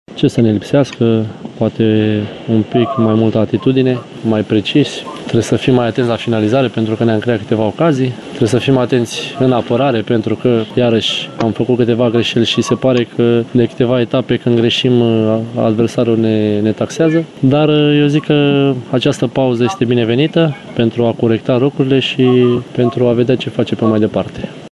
într-o declarație la Digi Sport